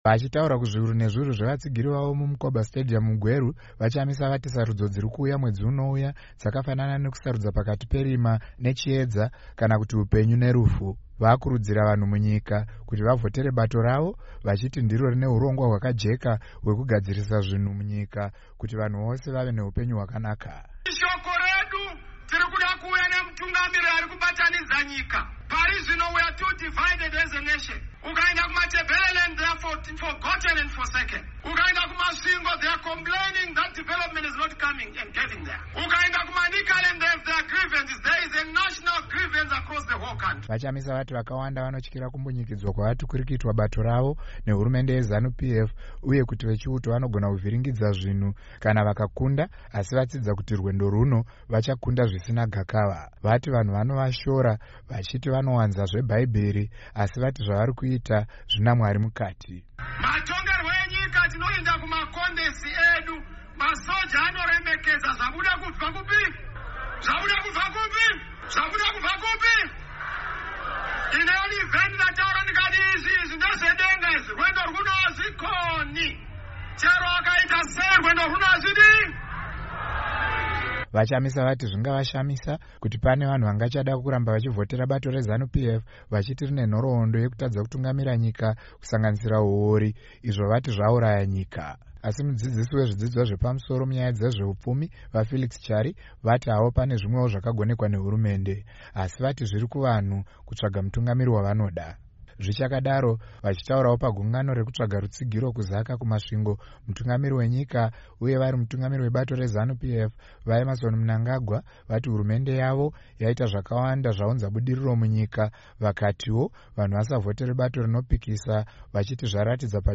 Vachitaura kuzviuru nezviuru zvevatsigiri vavo muMkoba Stadium VaChamisa vati sarudzo dziri kuuya mwedzi unouya dzakafanana nekusarudza pakati perima nechiedza kana kuti hupenyu nerufu.